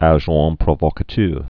(ă-zhäɴ prô-vôkä-tœr)